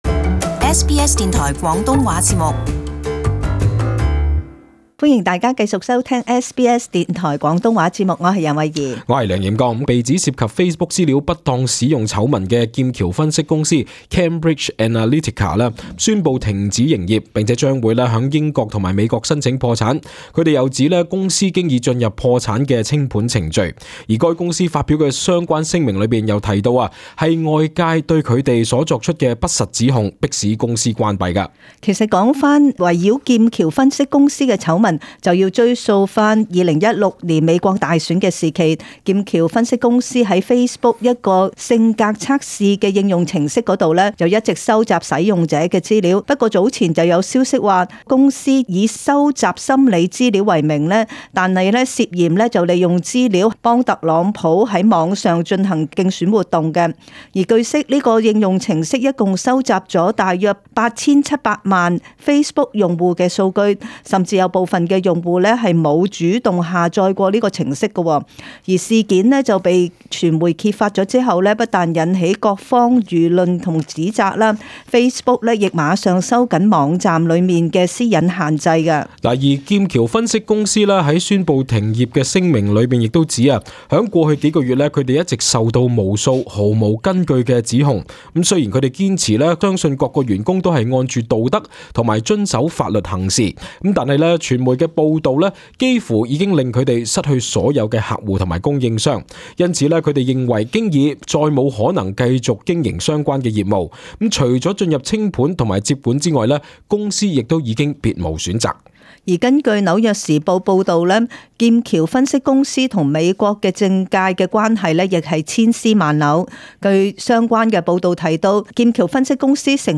【時事報導】劍橋分析公司宣布停業